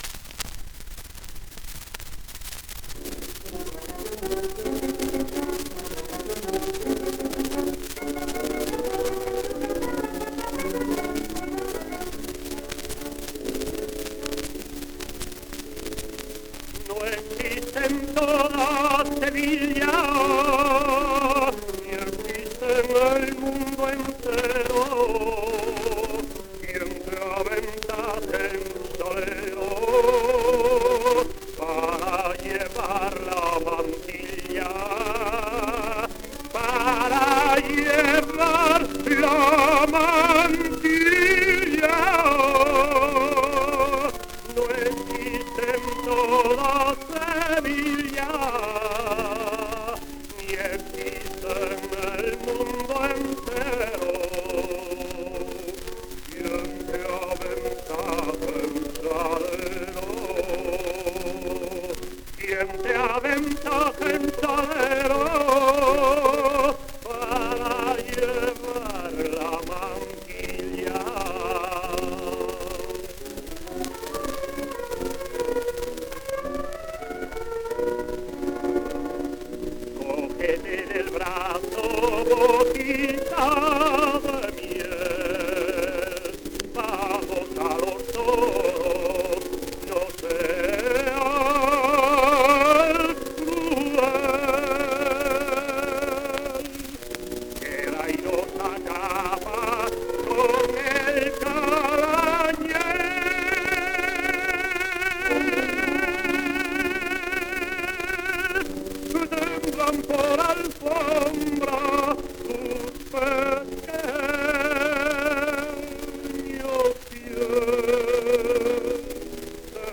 1 disco : 78 rpm ; 25 cm Intérprete